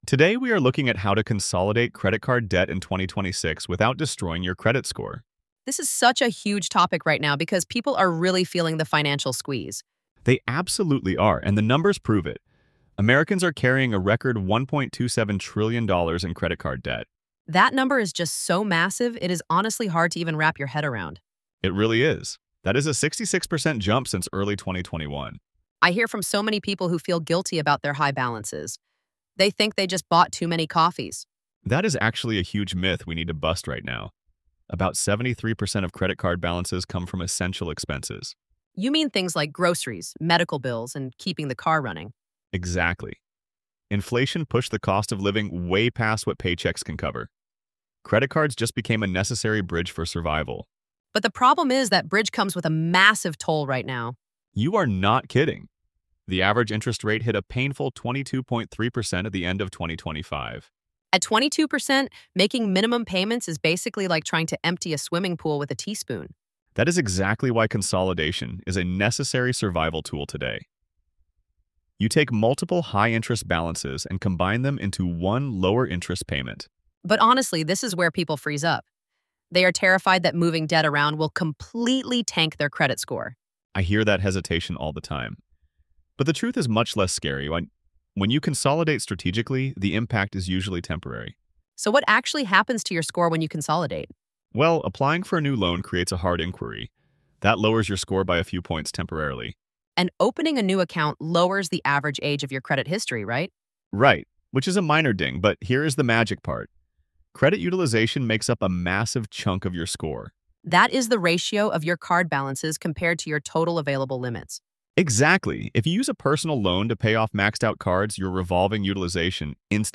AI-generated audio · Voices by ElevenLabs